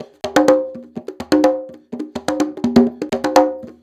Live Percussion A 04.wav